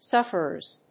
/ˈsʌfɝɝz(米国英語), ˈsʌfɜ:ɜ:z(英国英語)/